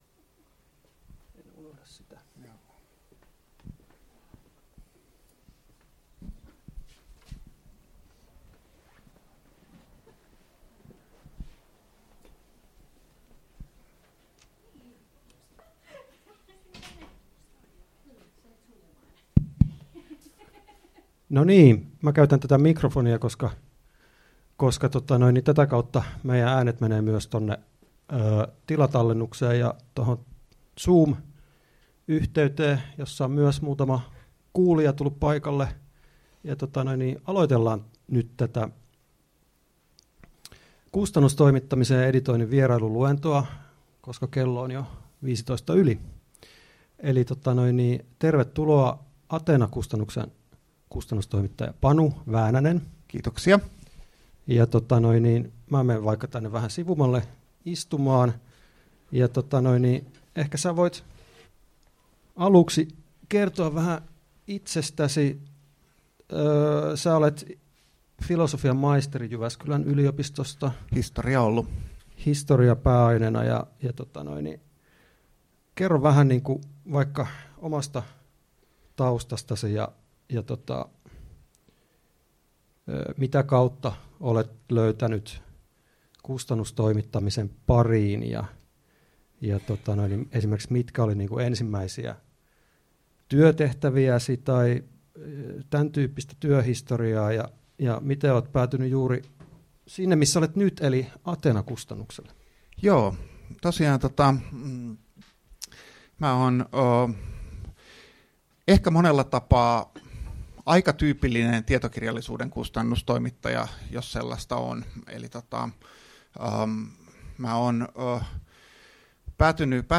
Luento 30.3.2023 — Moniviestin